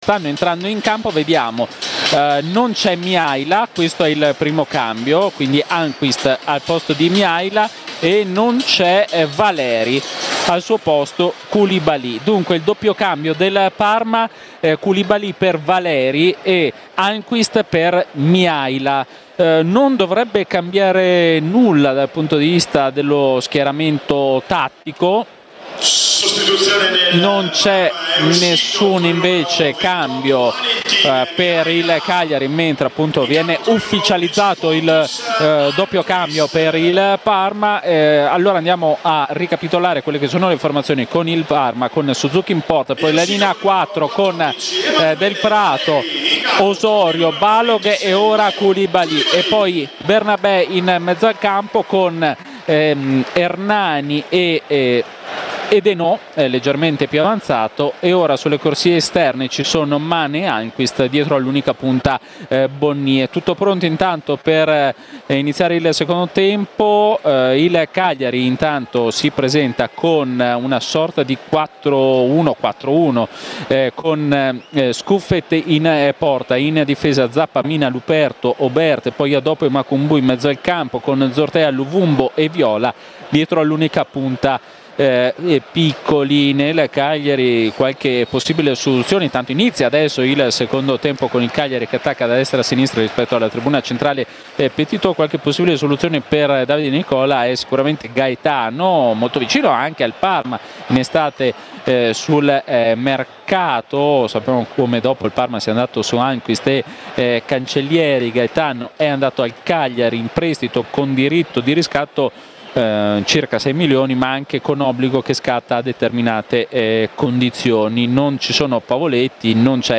Radiocronache Parma Calcio Parma - Cagliari - 2° tempo - 30 settembre 2024 Sep 30 2024 | 00:50:58 1x 00:00 / 00:50:58 Subscribe Share RSS Feed Share Link Embed